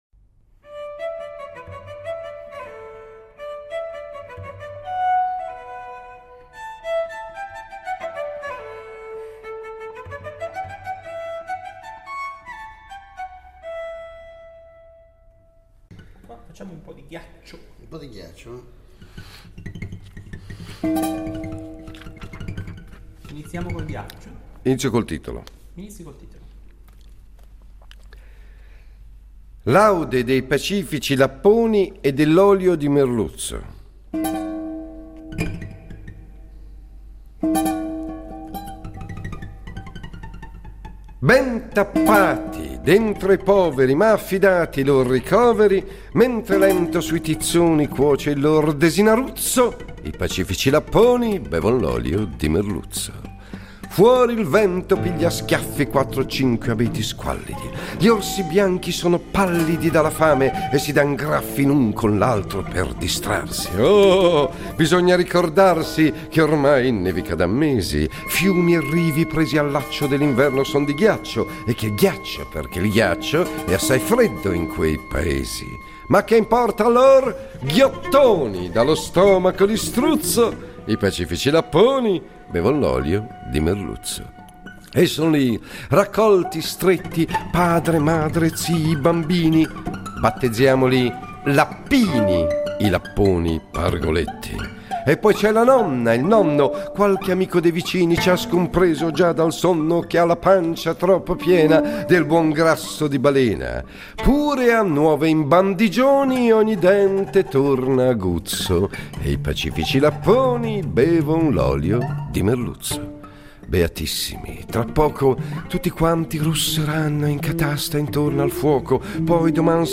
Attraverso le registrazioni dal vivo delle spericolate performaces poetico-musicale dell'autore, cantautore, attore e regista David Riondino, Colpo di poesia torna a omaggiare una delle voci più singolari della letteratura italiana a cavallo fra Ottocento e Novecento: il poeta piemontese Ernesto Ragazzoni (Orta San Giulio, 1870 – Torino, 1920).